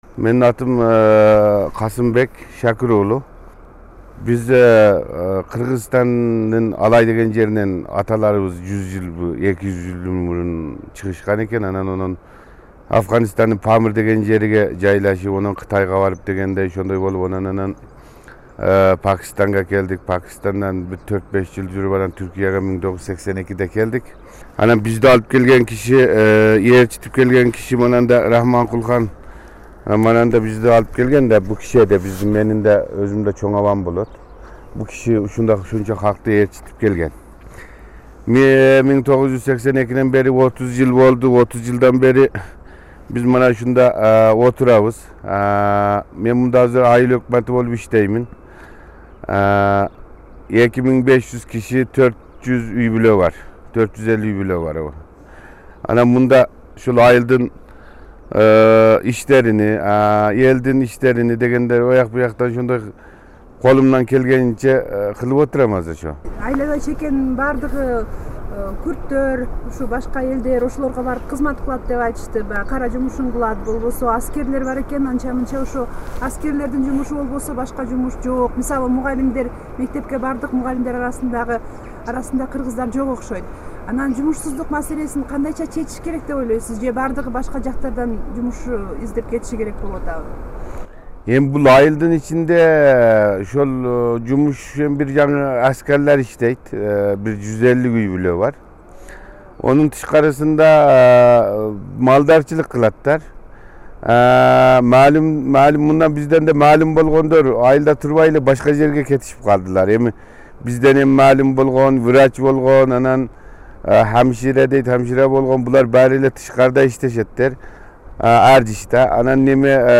Түркиядагы ооган кыргыздарынын айылын эл шайлаган айыл башчы Касымбек Шакир уулу Варол башкарат. "Улуу Памир - 35" түрмөгүнүн кезектеги санын ушул маекке орун беребиз.